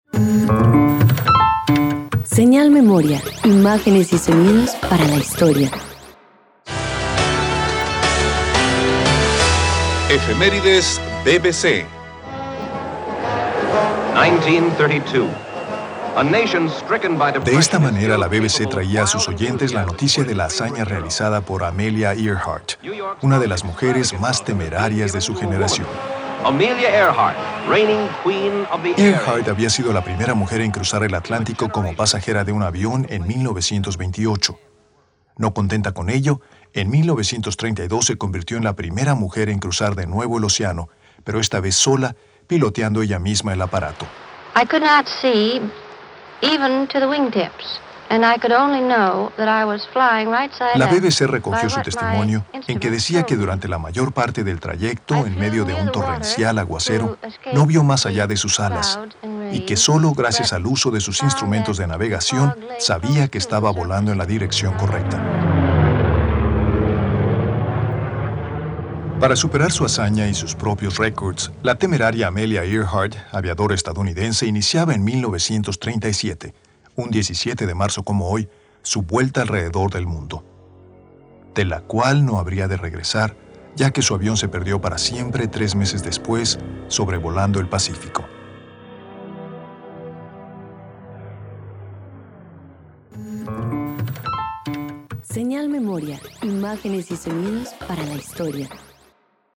Marzo] [Programa informativo].